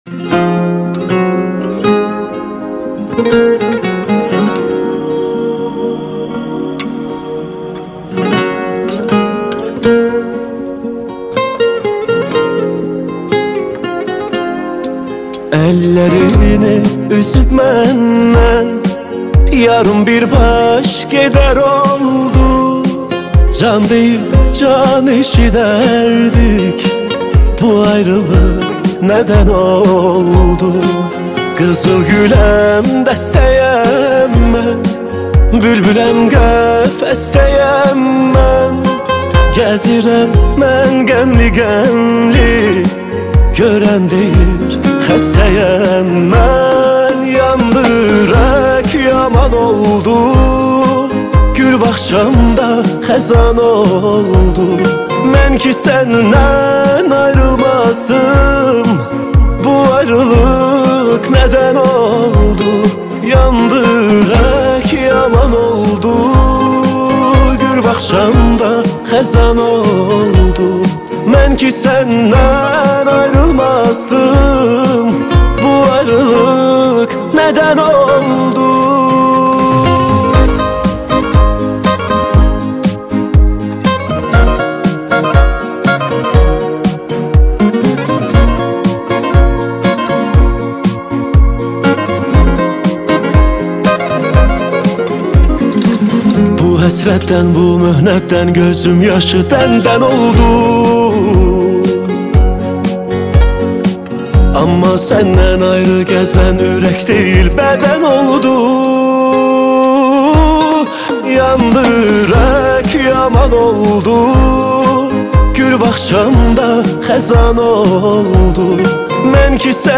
Azeri Muzik